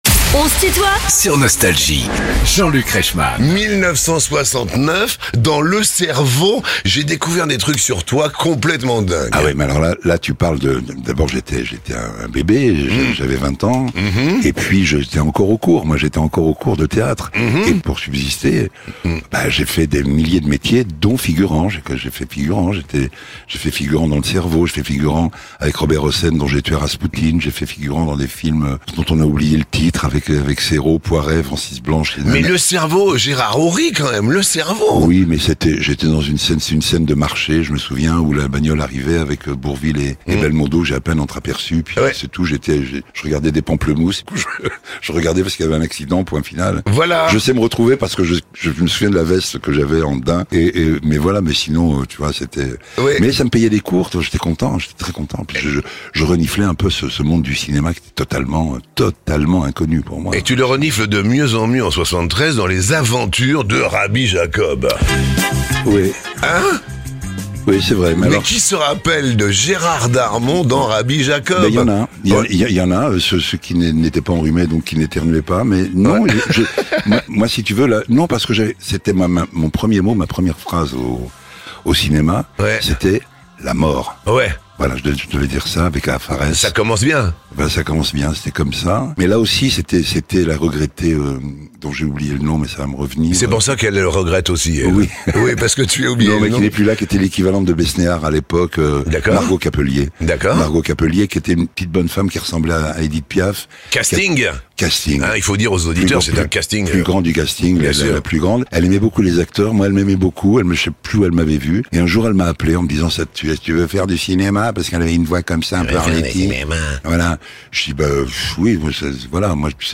Invité de "On se tutoie ?...", Gérard Darmon revient sur les moments forts de son incroyable carrière ~ Les interviews Podcast